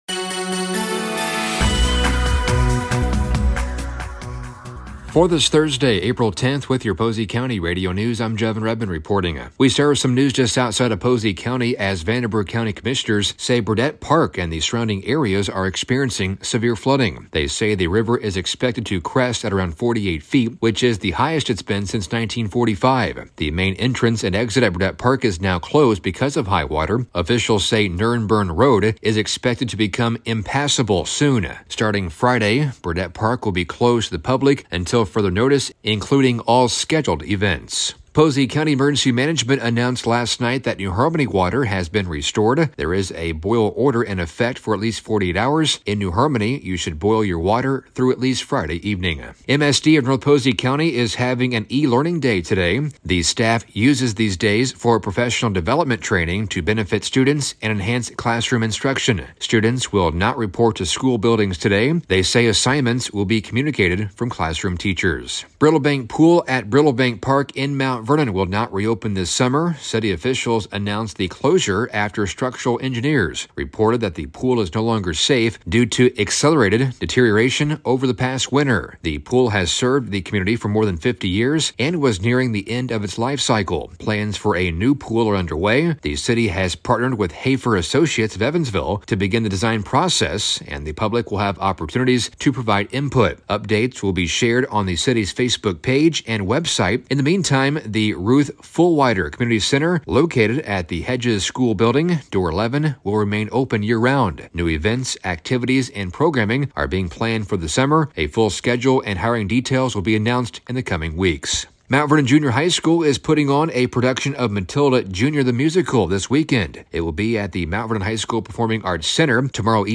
Local News: Thursday April 10th 2025